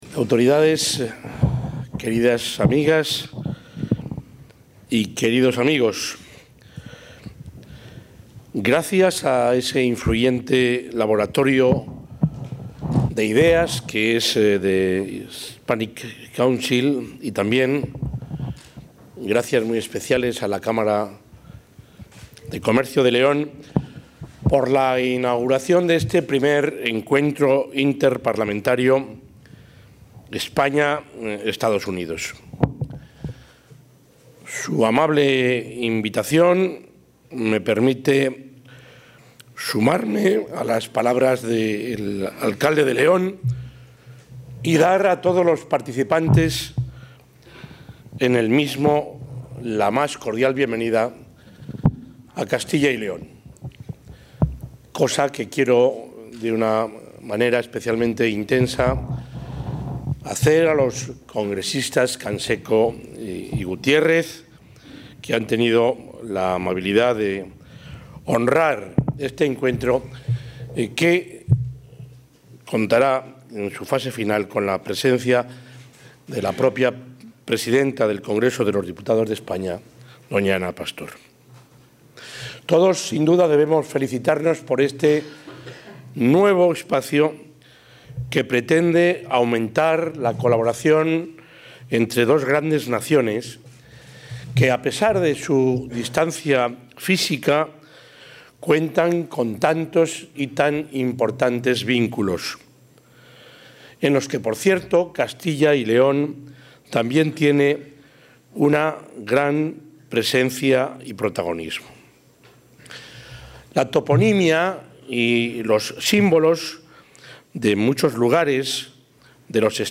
Durante la inauguración del I Encuentro Parlamentario España-EE. UU., celebrado en el Palacio del Conde Luna de León y...
El presidente de la Junta de Castilla y León, Juan Vicente Herrera, ha inaugurado esta mañana en León el I Encuentro Parlamentario España-EE. UU.